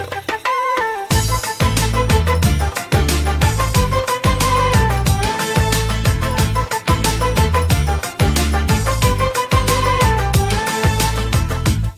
• Качество: 321, Stereo
поп
громкие
восточные мотивы
веселые
без слов
арабские